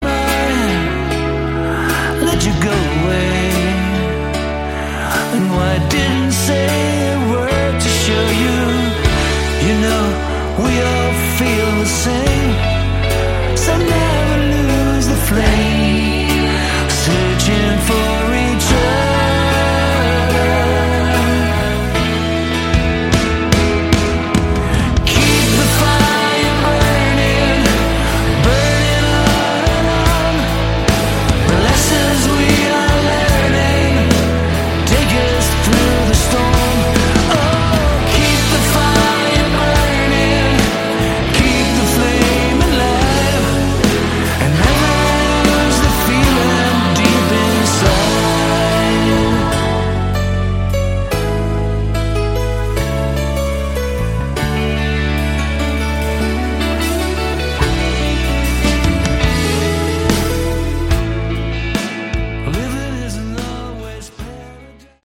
Category: AOR
lead Vocals
guitars
drums, backing vocals
bass
keyboards
backing vocals, percussion